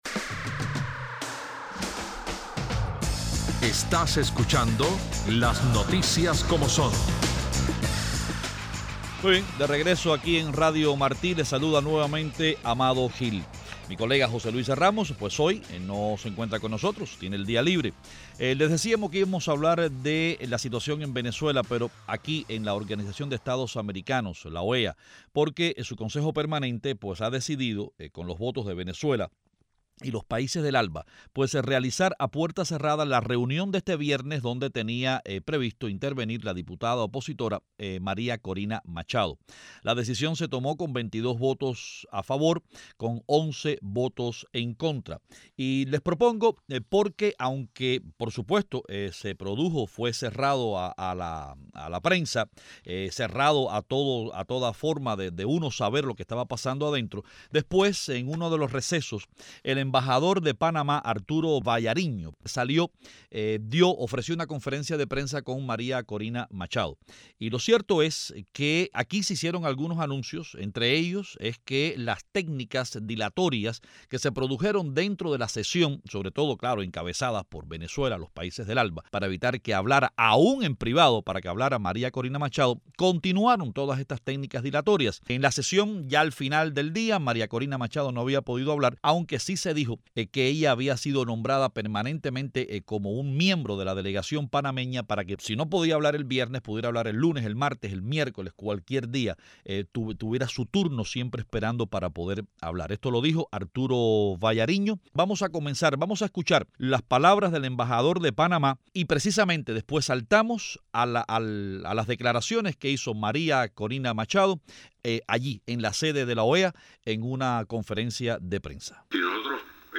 Con una votación 22 a 11 y una abstención, el Consejo Permanente de la Organización de Estados Americanos decidió celebrar a puertas cerradas la sesión en la cual la diputada opositora venezolana María Corina Machado iba a hablar de la situación política en su país. Ofrecemos las declaraciones que la Diputada ofreció durante su conferencia de prensa en Washington. También se nos une el ex embajador de Venezuela ante la OEA, José Hernández, quien analiza el significado de la votación del Consejo.